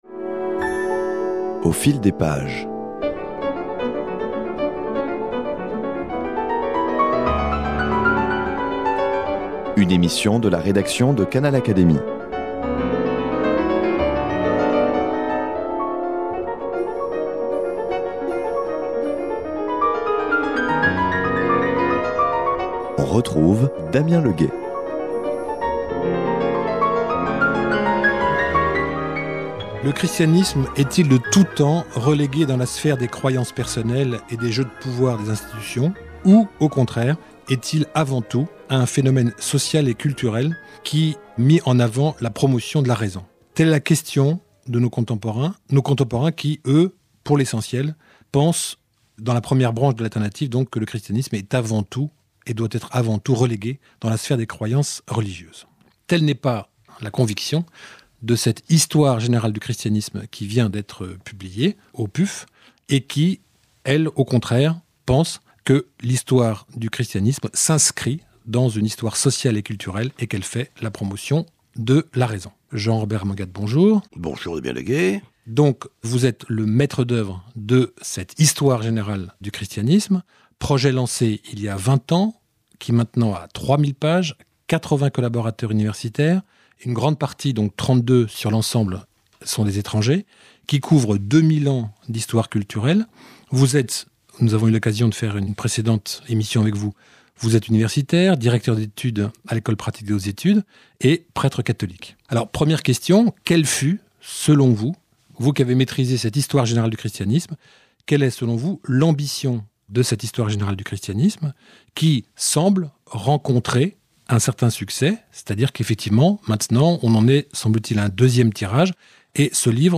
Dans l’entretien qu’il nous accorde, il insiste en particulier sur l’impact du christianisme sur les cultures et sur un christianisme qui a doté la pensée occidentale de ses structures conceptuelles.